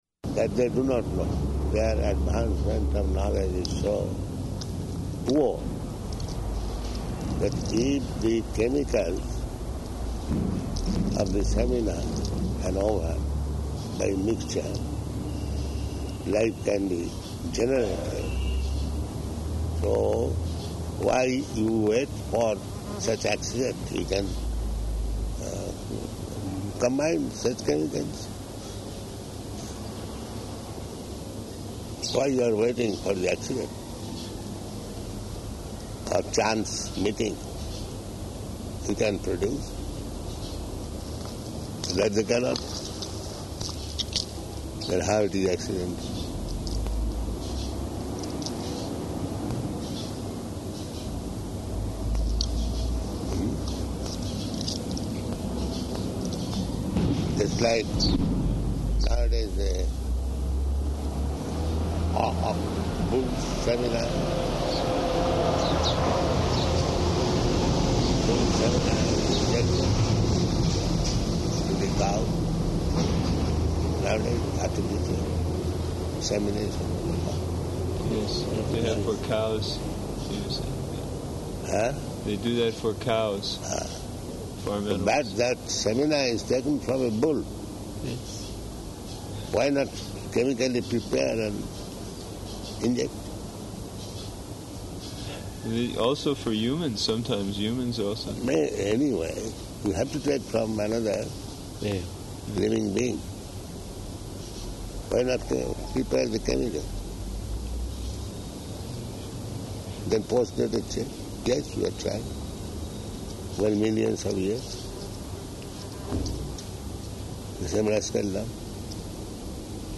Morning Walk --:-- --:-- Type: Walk Dated: August 12th 1976 Location: Tehran Audio file: 760812MW.TEH.mp3 Prabhupāda: That they do not know.